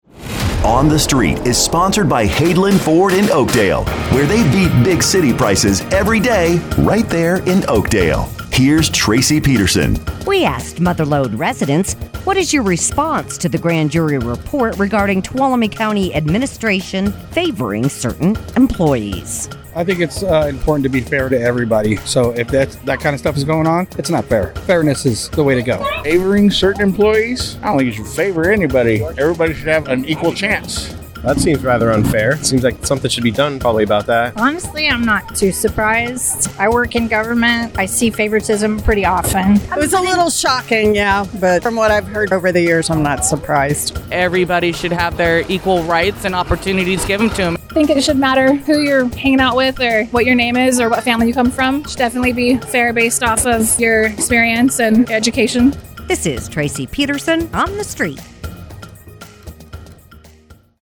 asks Mother Lode residents